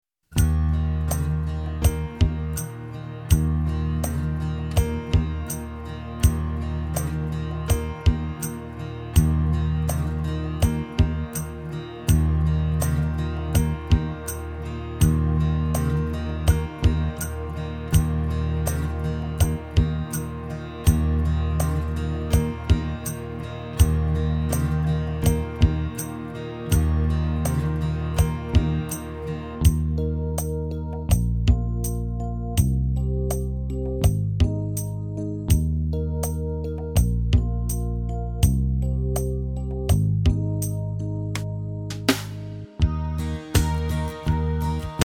Listen to the instrumental track.